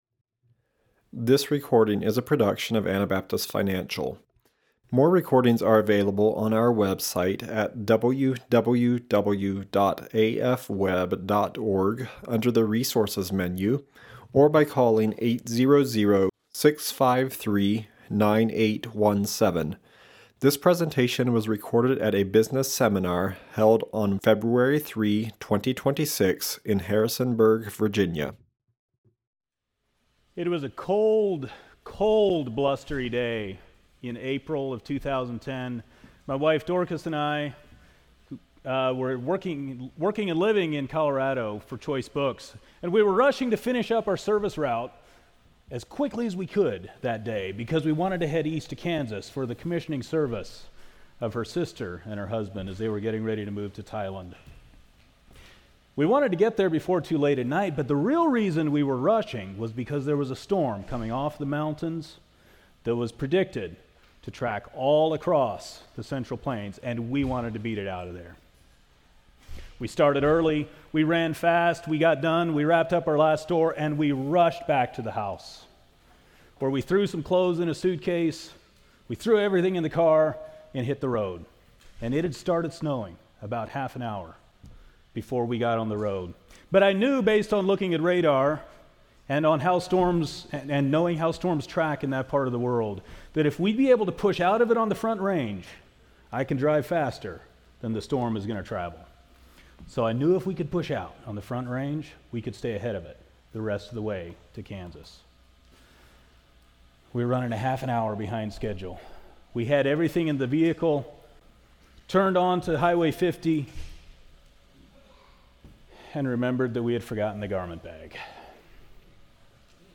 Virginia Business Seminar 2026